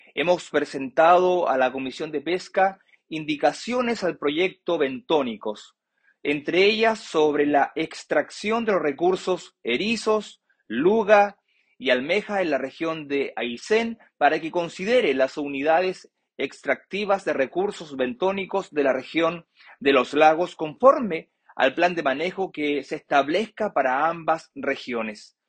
Como “la oportunidad para resolver, entre otros, el problema de las zonas contiguas”, calificó el diputado Mauro González, la presentación de una serie de indicaciones que, junto a los diputados Héctor Barría y Jaime Sáez, presentaron al proyecto de ley sobre recursos bentónicos. Dicha presentación fue realizada en la Comisión de Pesca, así lo confirmó el diputado González.